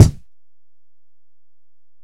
Kick (28).wav